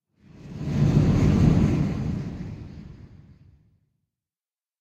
Minecraft Version Minecraft Version latest Latest Release | Latest Snapshot latest / assets / minecraft / sounds / ambient / cave / cave11.ogg Compare With Compare With Latest Release | Latest Snapshot
cave11.ogg